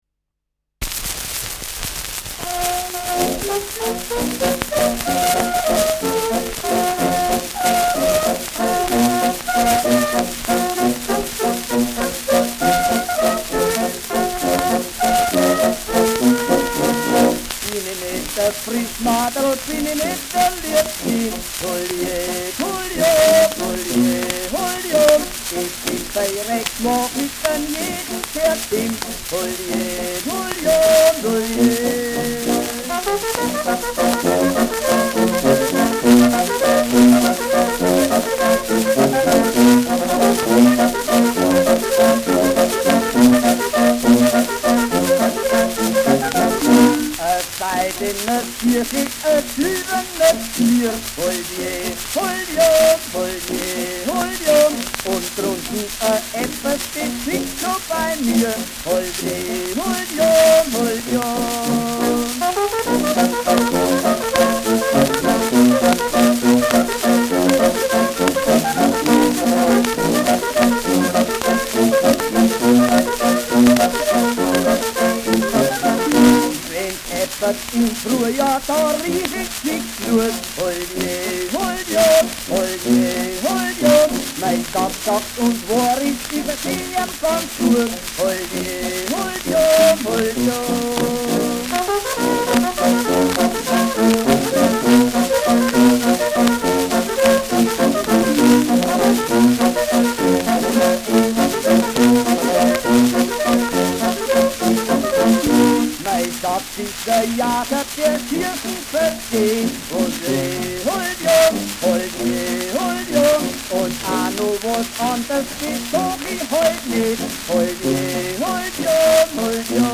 Schellackplatte
präsentes Rauschen
Fränkische Bauernkapelle (Interpretation)